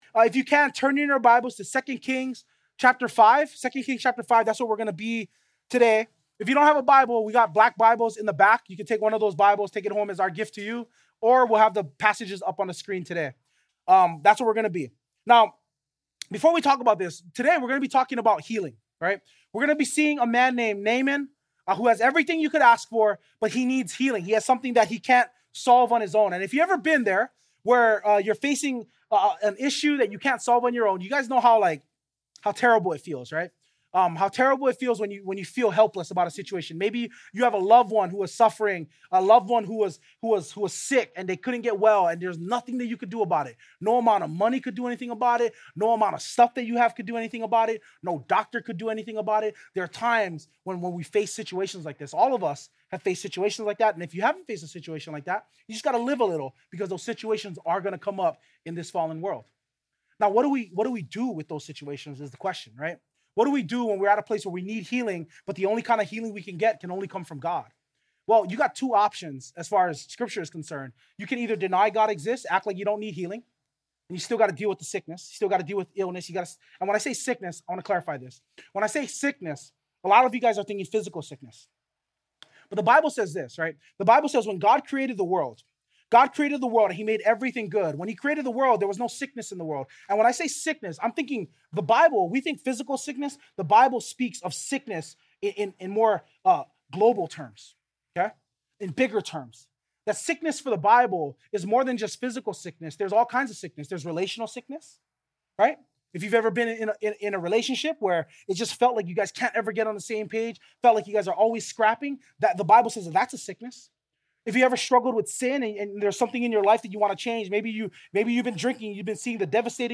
2025 Ultimate Healer Preacher